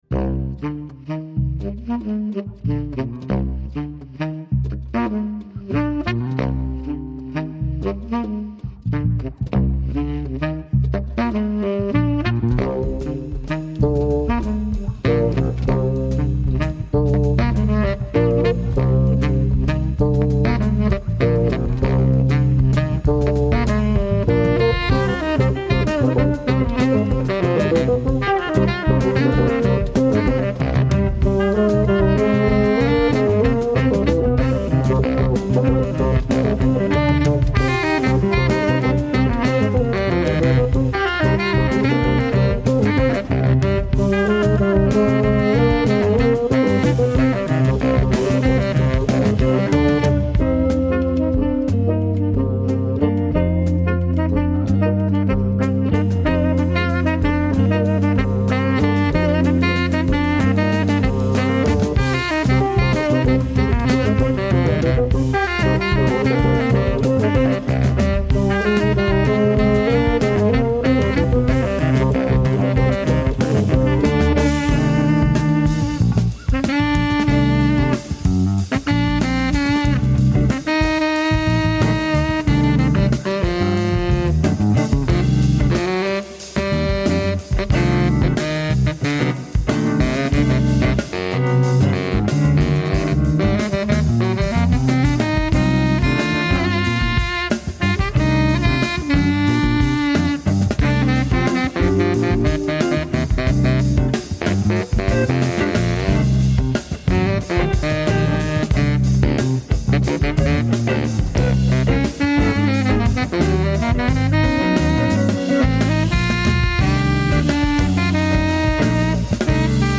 Incontro con il polistrumentista e compositore